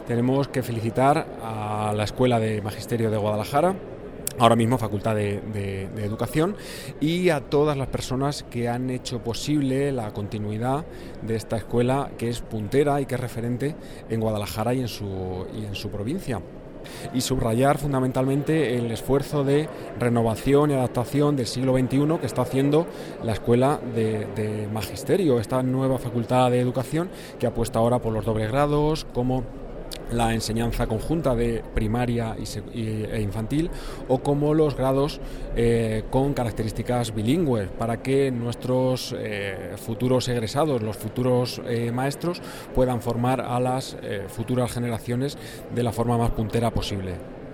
El director general de Universidades, Investigación e Innovación, Ricardo Cuevas, habla de la importancia de la escuela de magisterio de Guadalajara en su 175 aniversario.